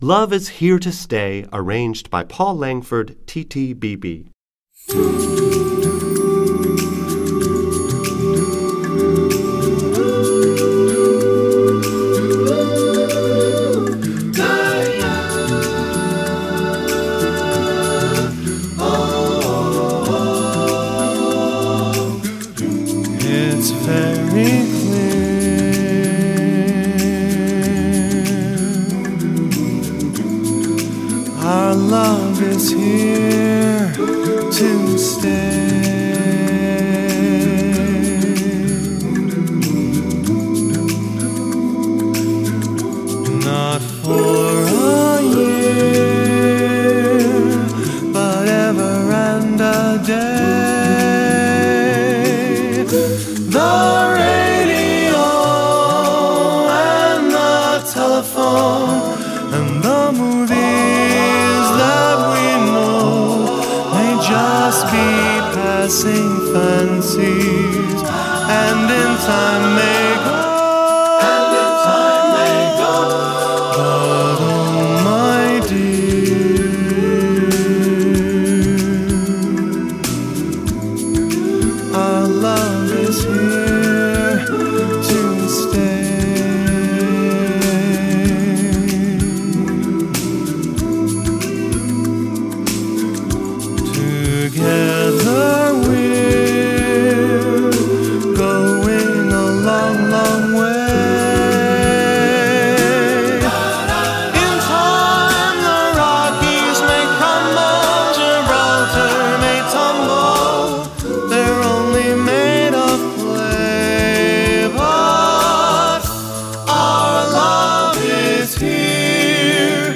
Voicing: TTBB divisi